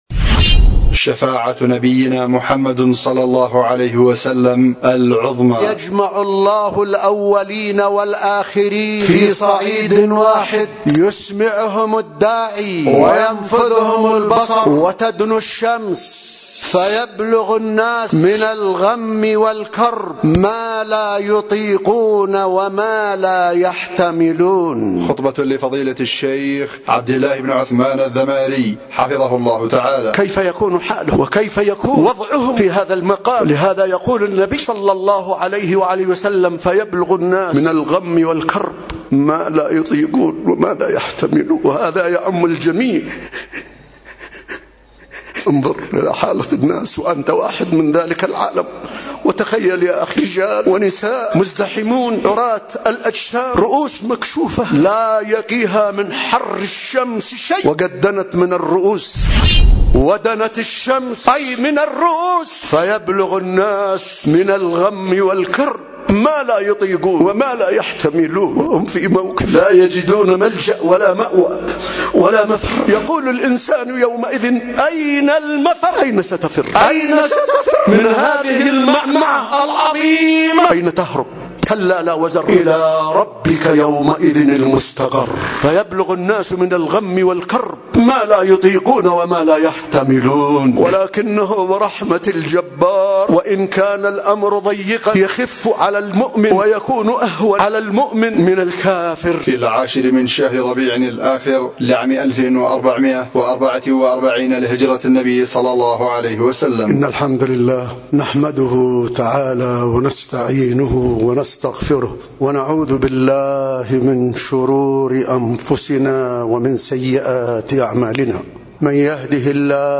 ألقيت في مسجد الصديق بمدينة ذمار